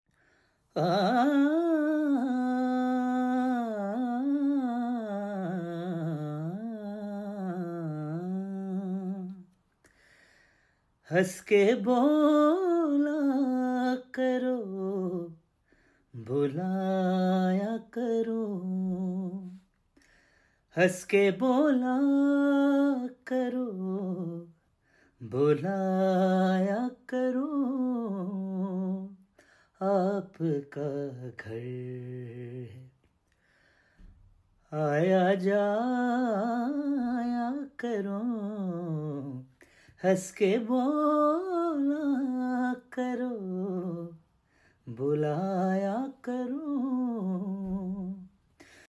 Singing One Of My Fav Sound Effects Free Download